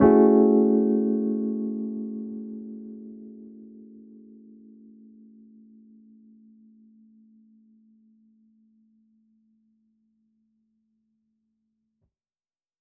Index of /musicradar/jazz-keys-samples/Chord Hits/Electric Piano 3
JK_ElPiano3_Chord-Amaj13.wav